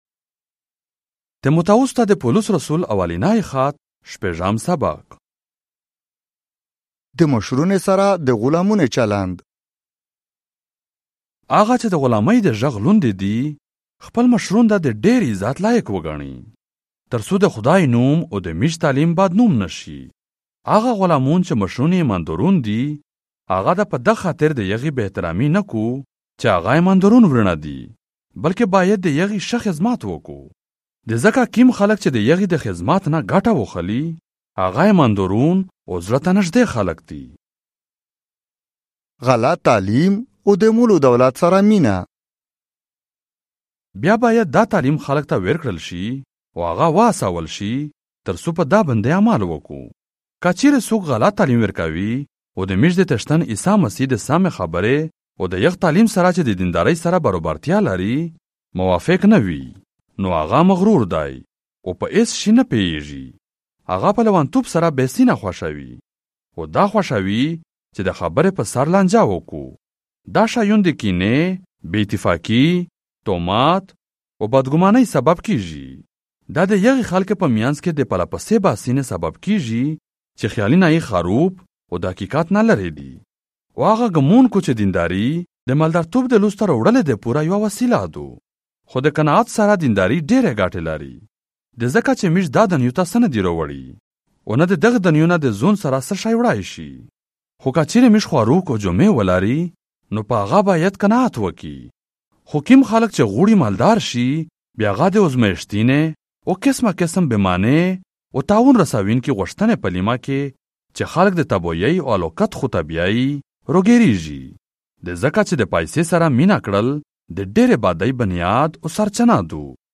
1 Timothy - Chapter 6 in the Pashto language, Central - audio 2025